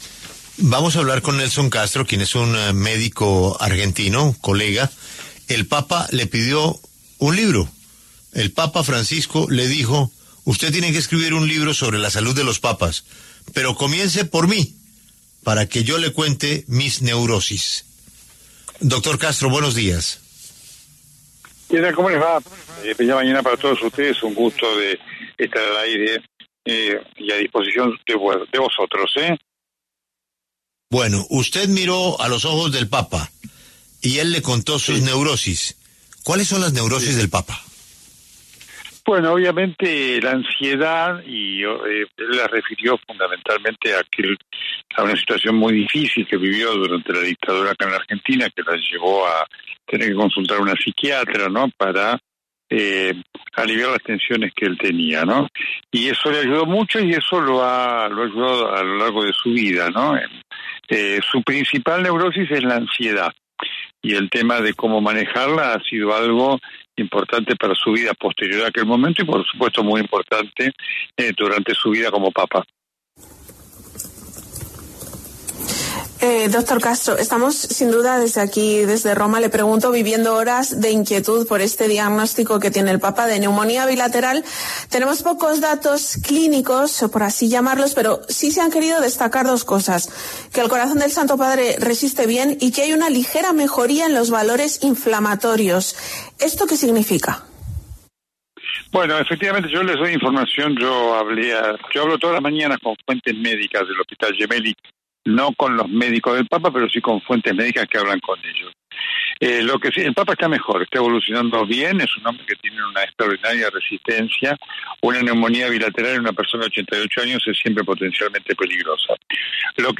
Nelson Castro, periodista y médico argentino, conversó con La W sobre la salud del papa Francisco, pues el sumo pontífice habló abiertamente de su salud con él para la creación del libro ‘La salud de los papas’.
Nelson Castro pasó por los micrófonos de La W para hablar sobre este encargo, lo que el papa le contó y algunos detalles de su estado de salud actual.